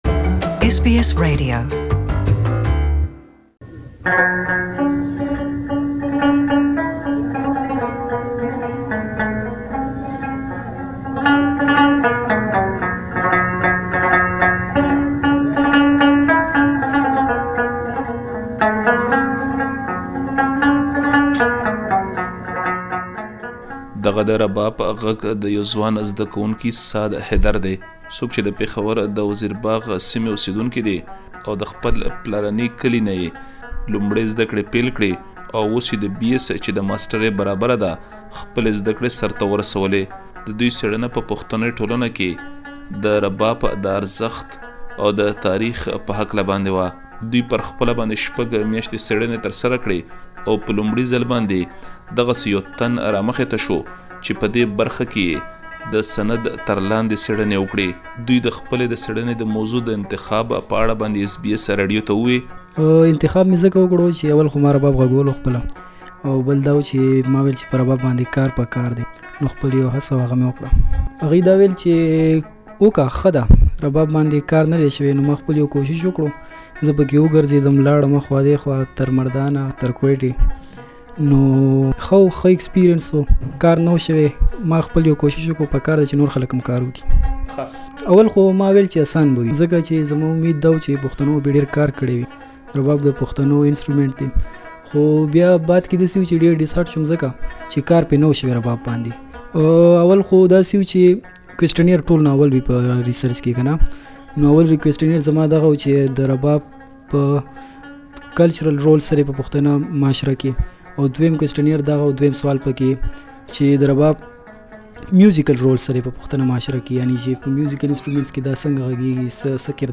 Please listen to the full interview in Pashto language.